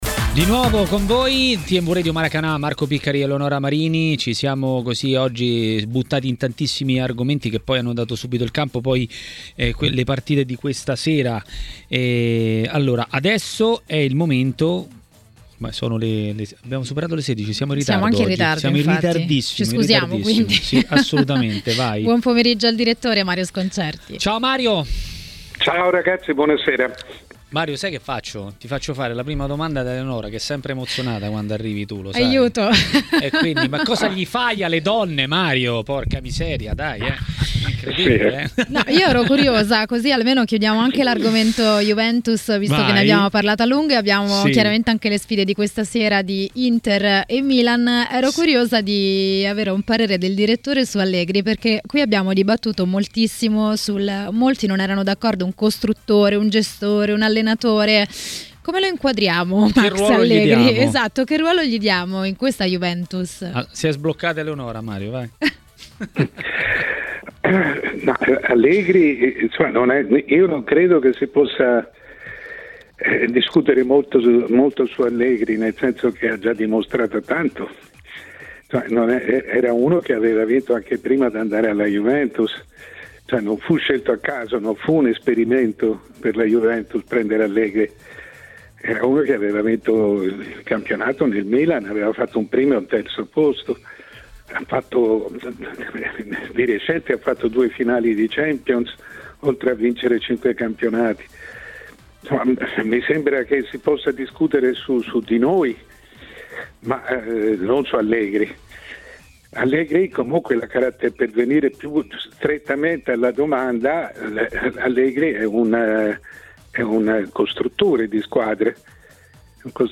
A commentare la Champions a TMW Radio, durante Maracanà, è stato il direttore Mario Sconcerti.